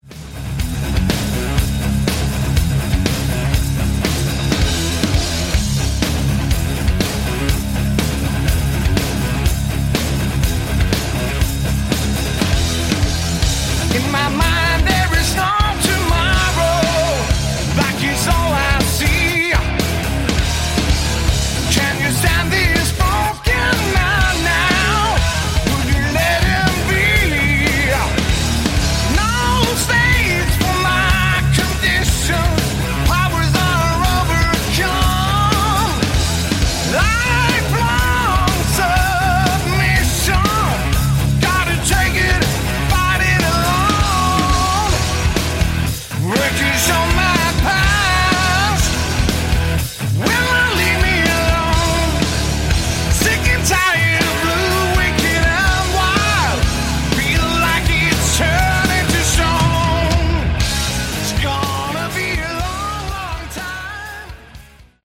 Category: Hard Rock
lead vocals
guitar, vocals
backing vocals